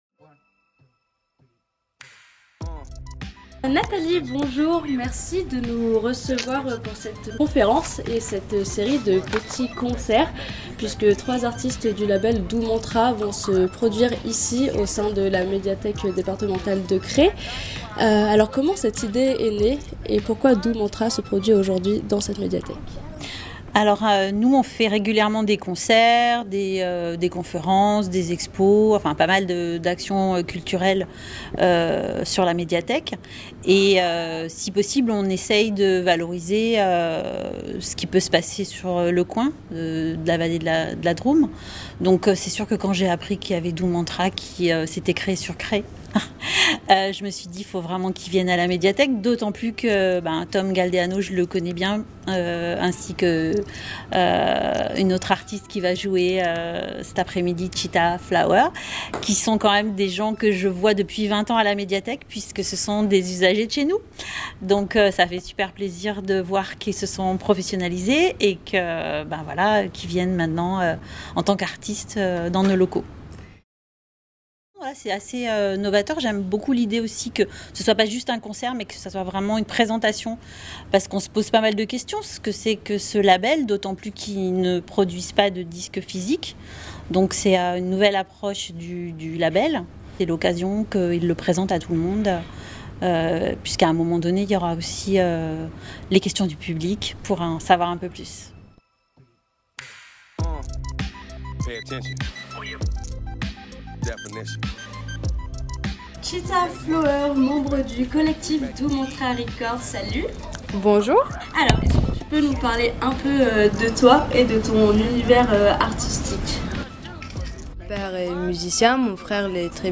Le collectif d’artistes DOUX MANTRA RECORDS à réalisé à la médiathèque Départementale de la Drôme à Crest un Showcase ouvert au public permettant de présenter l’association, ses projets, mais aussi pour une sensibilisation autour de la thématique de l’industrie de la musique à notre époque. à la suite de cette présentation, trois artistes de ce collectif ont réalisé une prestation live présentant quelques uns de leurs morceaux